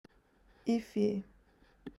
Listening Pronunciation Activity